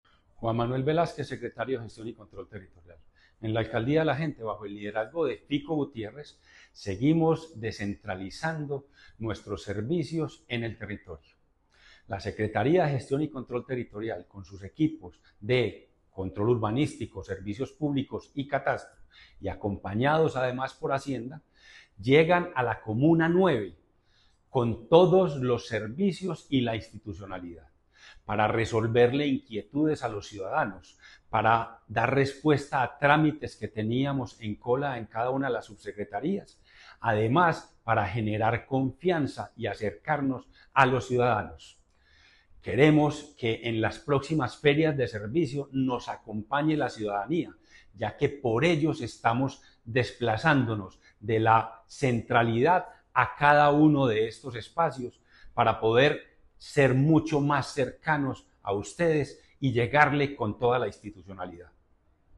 Palabras de Juan Manuel Velásquez Correa, secretario de Gestión y Control Territorial